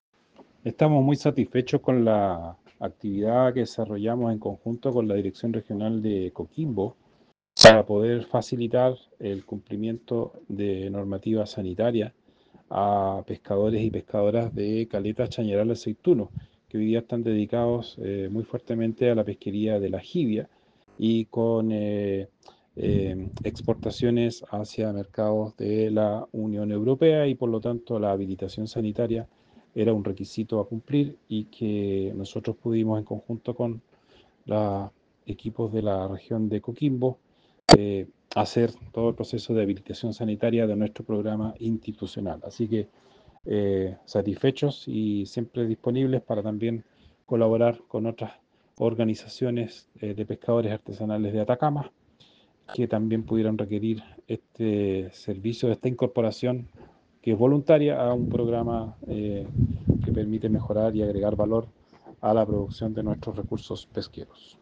Por su parte, el director regional de Sernapesca Atacama, Guillermo Rivera, destacó el trabajo coordinado entre ambas regiones “En esta actividad se reafirma el trabajo conjunto entre las distintas unidades territoriales de Sernapesca, que están prestas para realizar las labores que requieren nuestros usuarios, garantizando el cumplimiento normativo en torno a las habilitaciones sanitarias”.